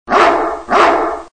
Dzwonki na telefon Szczekanie Psa
Kategorie Zwierzęta